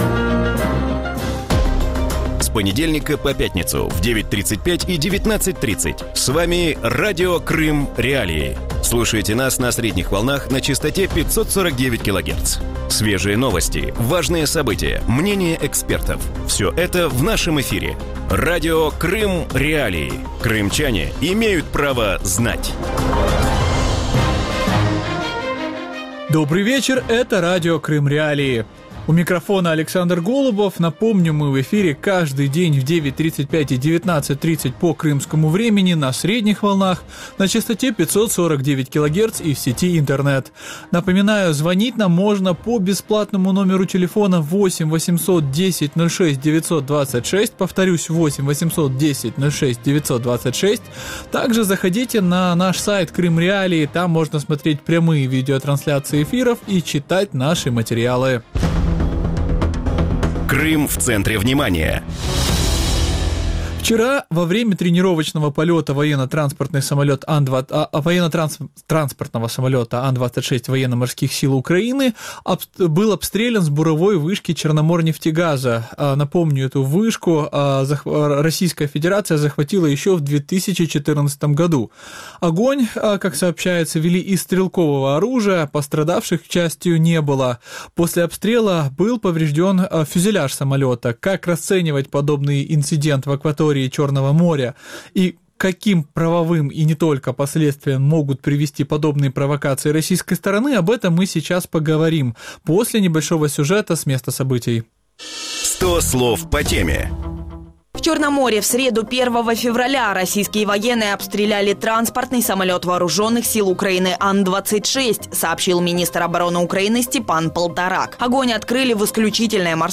У вечірньому ефірі Радіо Крим.Реалії говорять про обстріл українського військово-транспортного літака АН-26 над Чорним морем. Над чиєю територією був атакований українських літак, до яких наслідків може призвести обстріл і як Україна повинна реагувати на військові провокації?